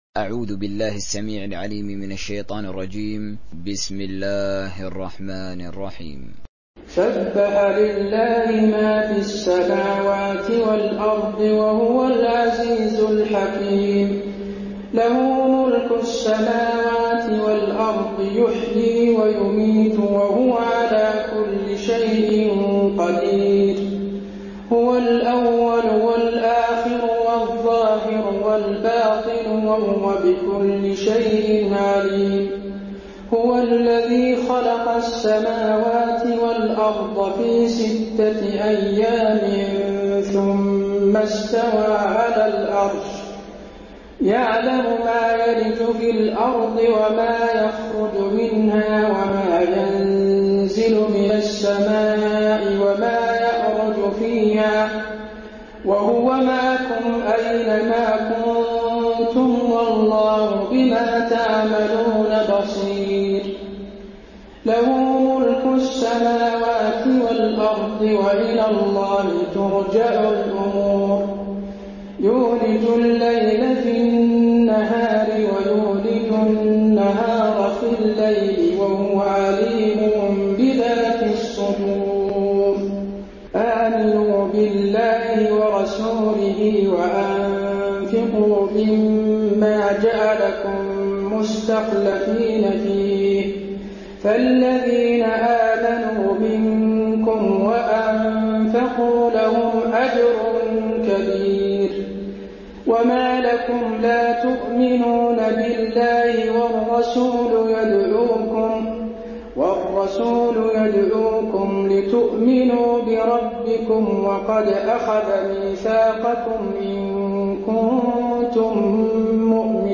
تحميل سورة الحديد mp3 بصوت حسين آل الشيخ تراويح برواية حفص عن عاصم, تحميل استماع القرآن الكريم على الجوال mp3 كاملا بروابط مباشرة وسريعة
تحميل سورة الحديد حسين آل الشيخ تراويح